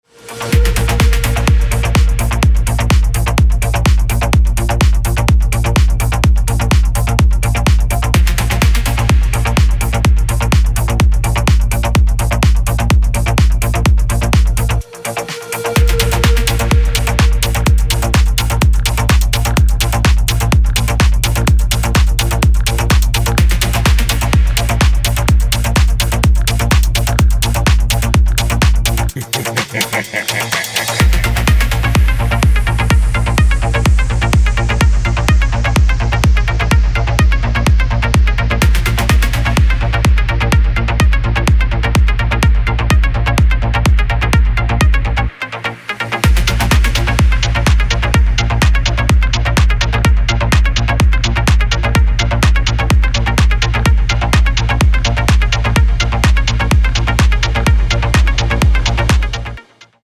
Melodic House & Techno